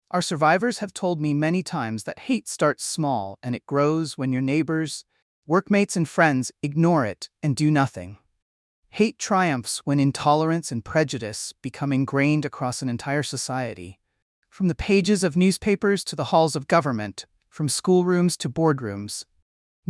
Sample answer: